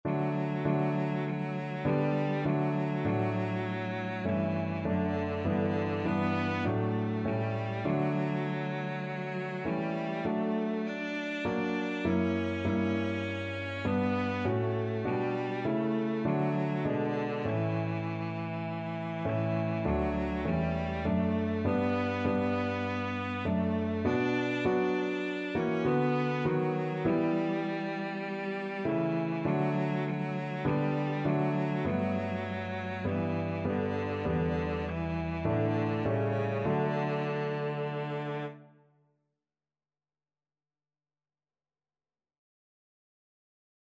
4/4 (View more 4/4 Music)
Classical (View more Classical Cello Music)